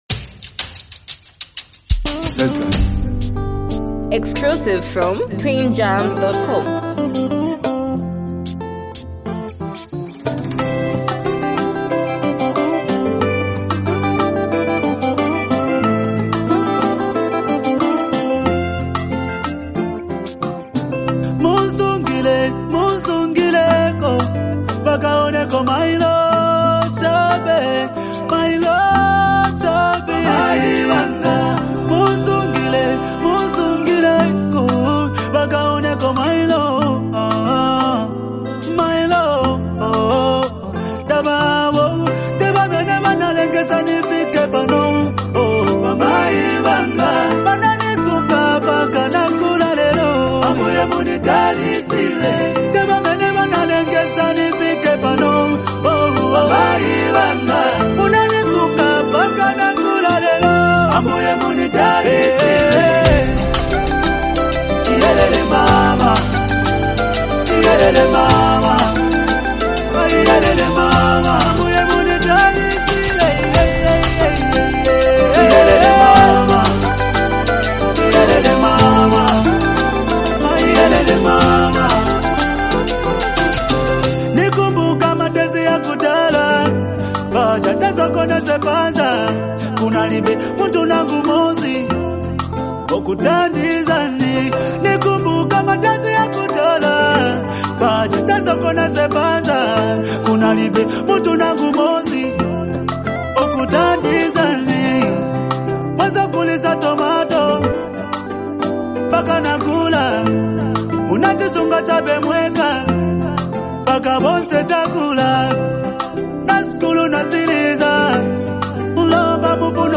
brings hype, emotional vibes, and a powerful hook
unique singing flow